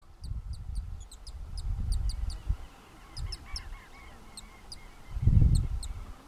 Alegrinho-trinador (Serpophaga griseicapilla)
Nome em Inglês: Straneck´s Tyrannulet
Fase da vida: Adulto
País: Argentina
Localidade ou área protegida: General Conesa
Condição: Selvagem
Certeza: Observado, Gravado Vocal